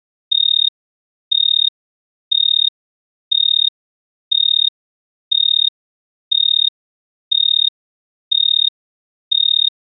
Basically, I am creating simulations of tree-cricket songs at different temperatures. Most tree crickets sing long trills consisting of a pure-toned pip repeated a certain number of times per second.
chirps grouped in 2-3- or 2-3-3 patterns of pulses that I might want to try and simulate.